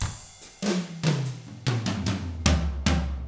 146BOSSAF3-L.wav